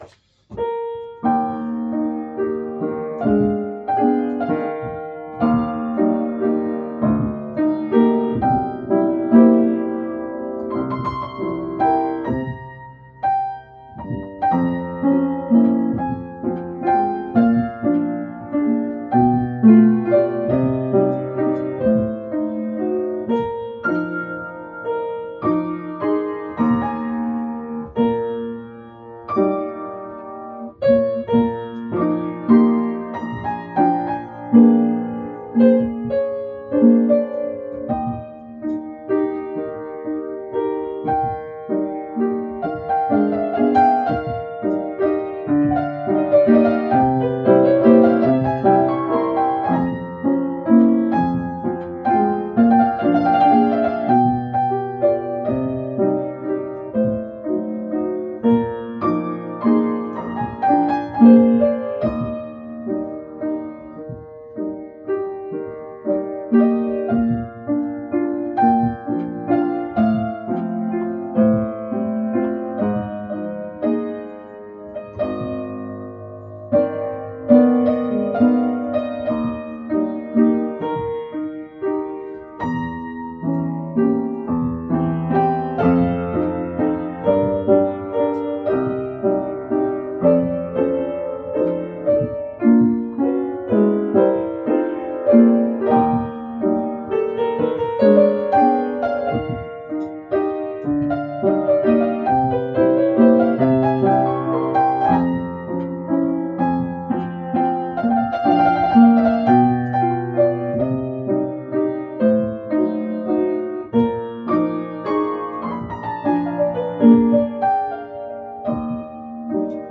piano_chopin_noc_op9_no2_v1.mp3